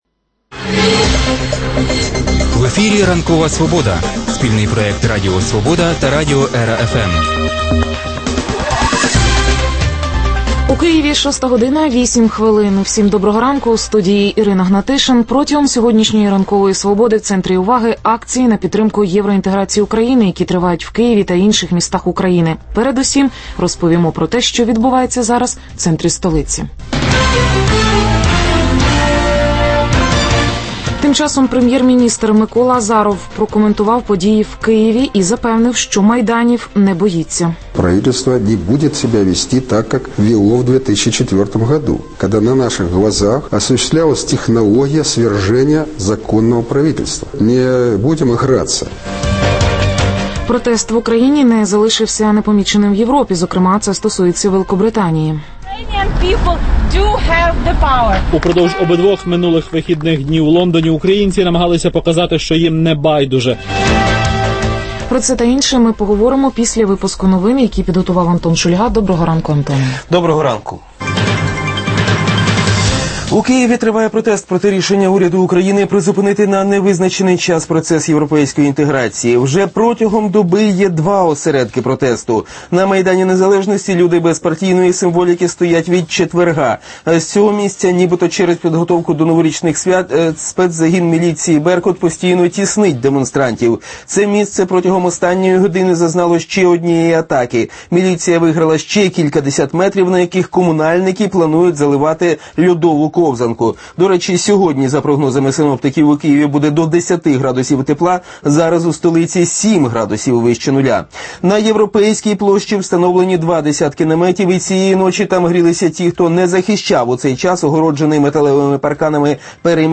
Прямі включення з Євромайдану в Києві.